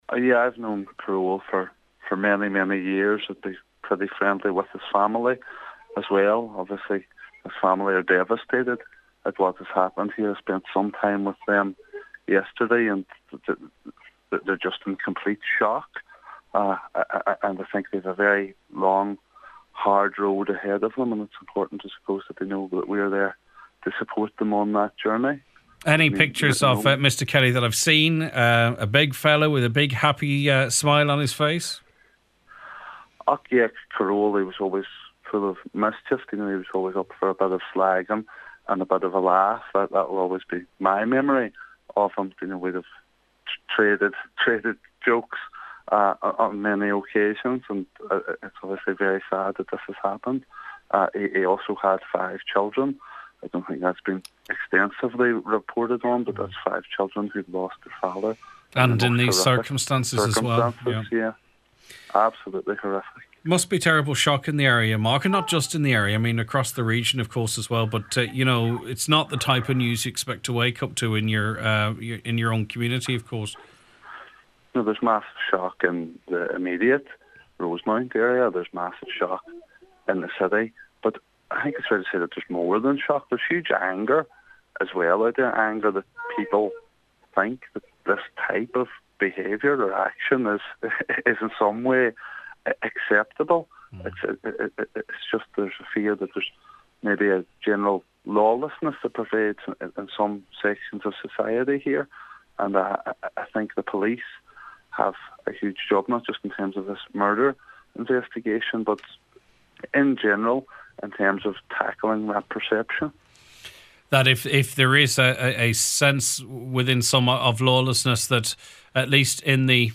On the Nine til Noon Show a short time ago, he spoke